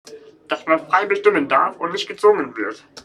Standort der Erzählbox:
MS Wissenschaft @ Diverse Häfen